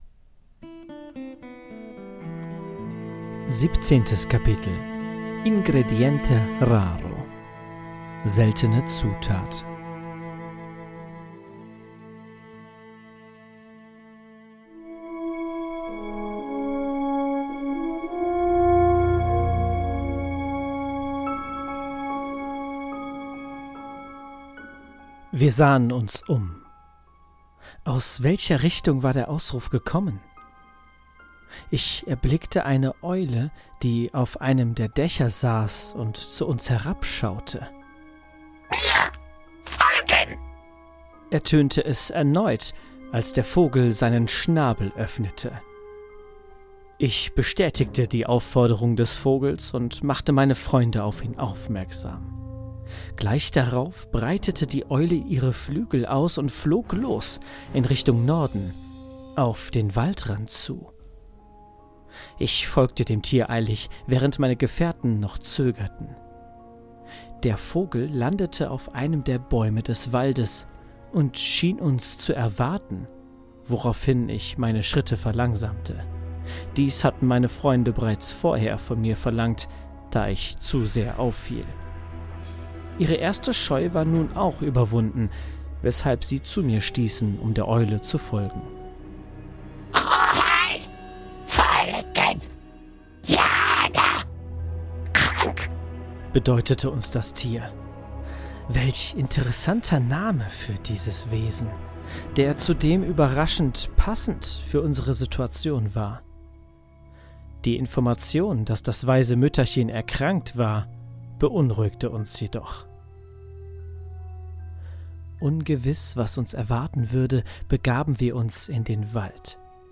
Die Calleano-Chroniken – Ein Hörbuch aus der Welt des schwarzen Auges